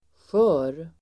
Uttal: [sjö:r]